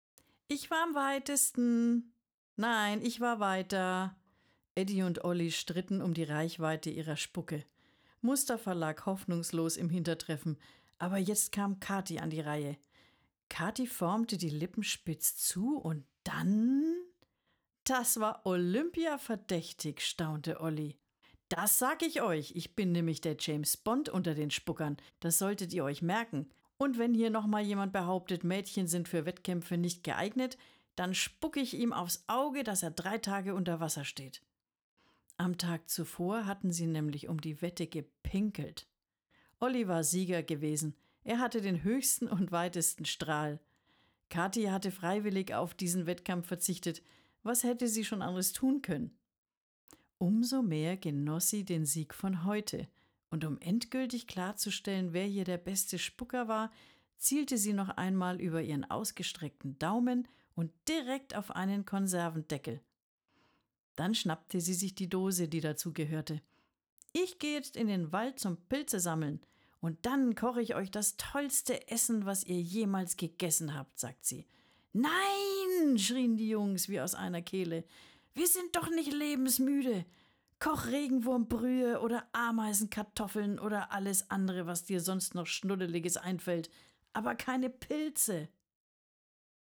Du bist auf der Suche nach einer weiblichen angenehmen Stimme?